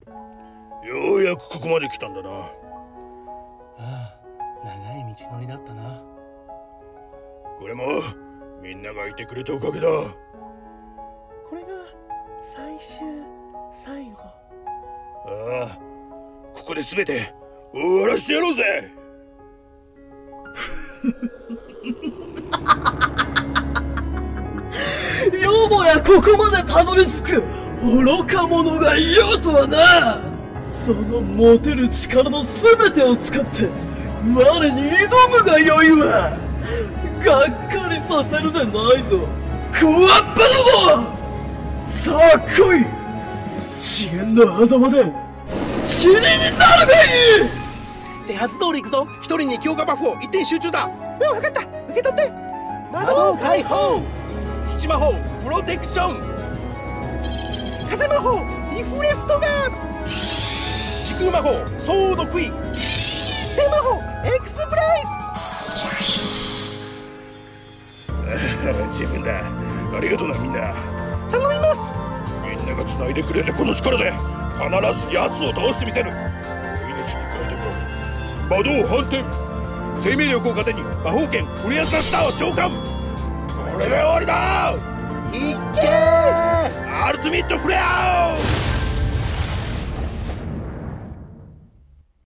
四人声劇台本【最終決戦⚔🪄✡